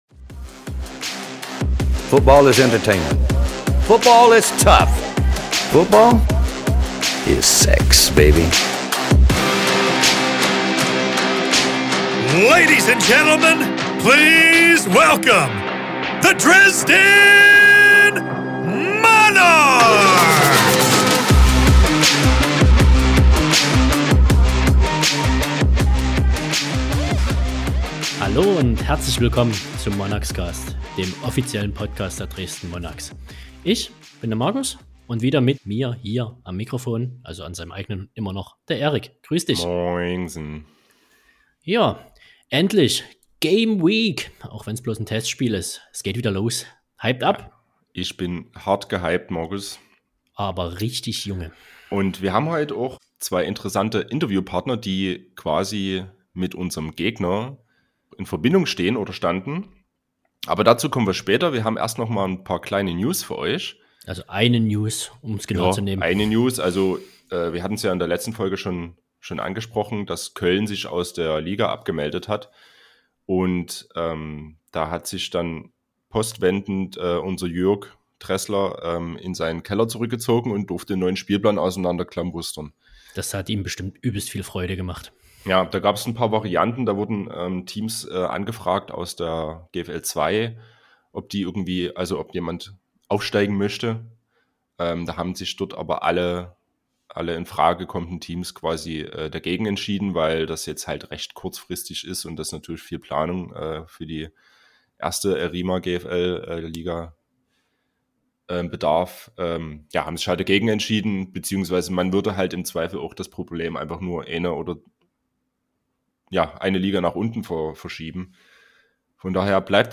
Außerdem wird immer ein interessanter Gast in einem Interview Rede und Antwort stehen.